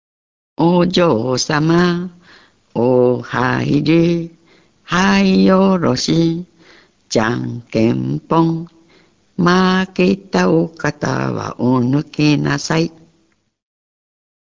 縄とびの唄